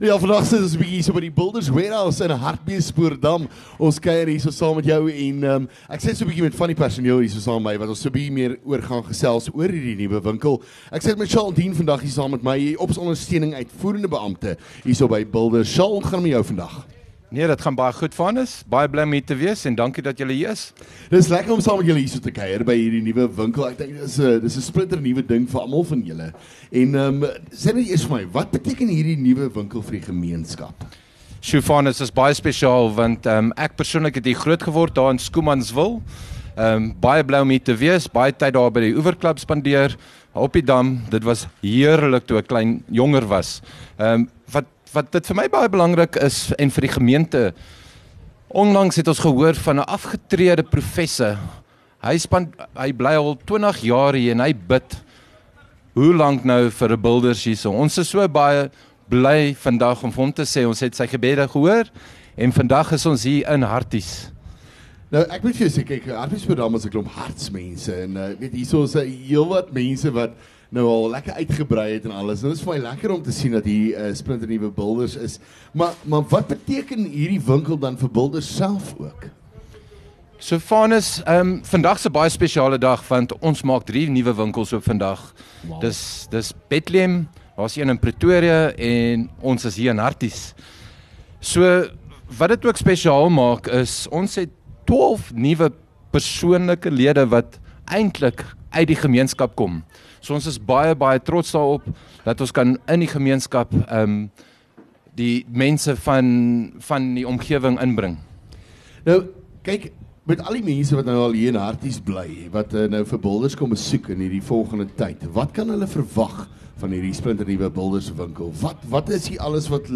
LEKKER FM | Onderhoude 28 Nov Builders Wharehouse